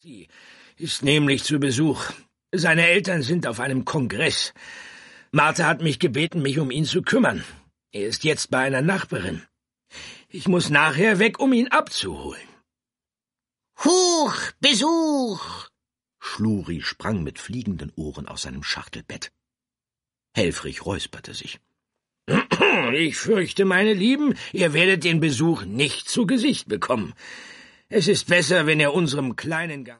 Ravensburger Schluri Schlampowski und der Störenfried ✔ tiptoi® Hörbuch ab 4 Jahren ✔ Jetzt online herunterladen!